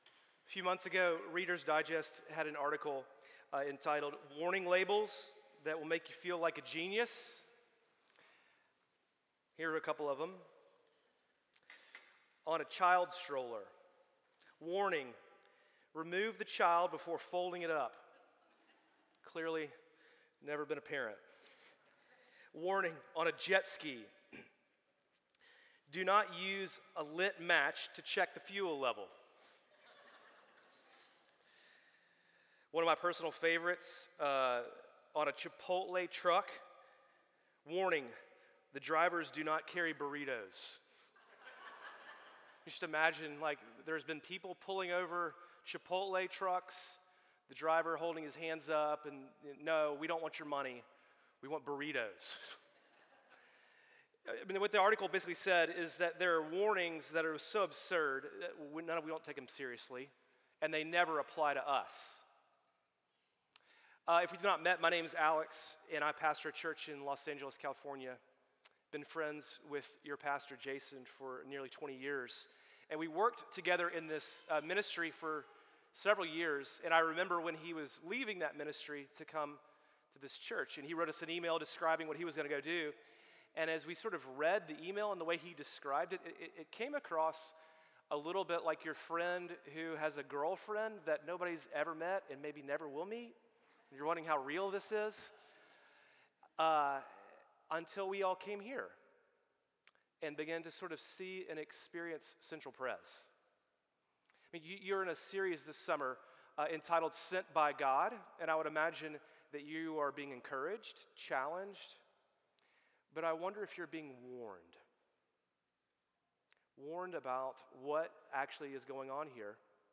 When ministry goes well, the danger of success creeps in, tempting us to chase a name for ourselves instead of elevating the name of the One to whom we owe credit for all our successes. Watch this sermon